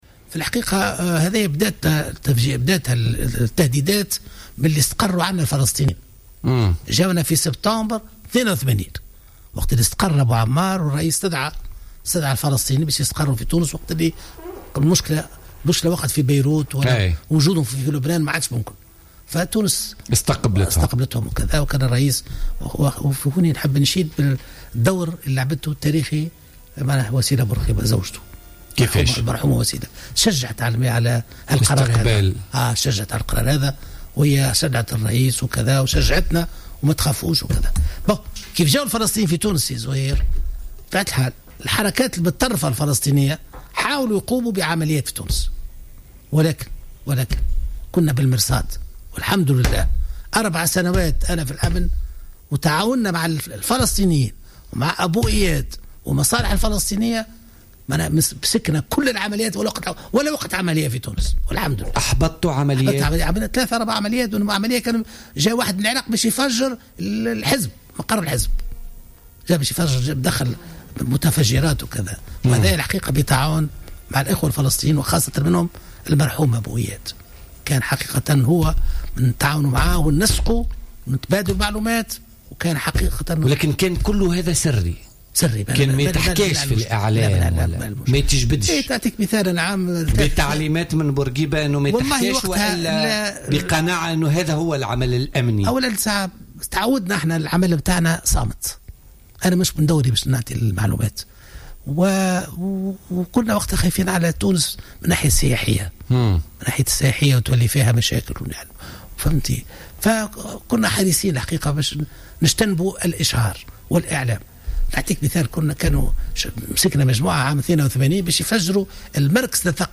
قال أحمد بنّور مدير الأمن والمخابرات وكاتب الدولة للدفاع في عهد الرئيس الحبيب بورقيبة في تصريح للجوهرة أف أم في برنامج بوليتكا لليوم الخميس 24 مارس 2016 إن التهديدات الإرهابية في تونس بدأت في سبتمبر سنة 1982 تاريخ دخول الفلسطينيين للبلاد بعد استدعائهم من قبل بورقيبة واستقرار أبو عمار في تونس.